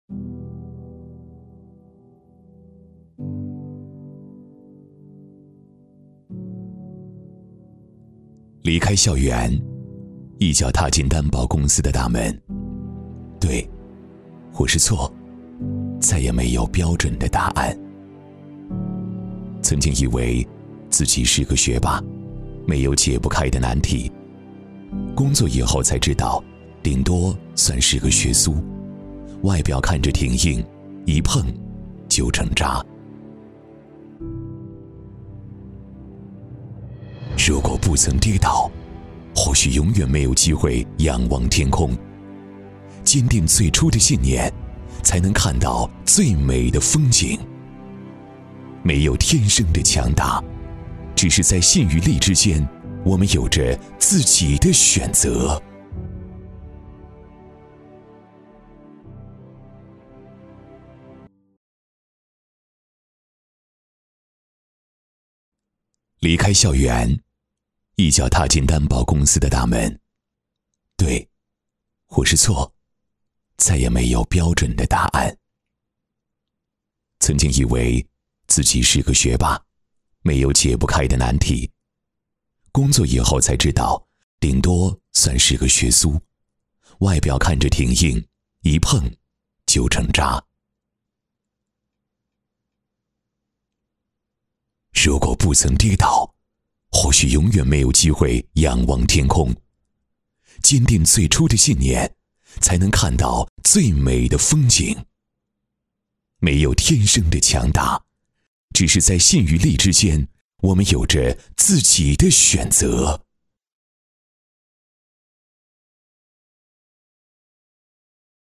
男124号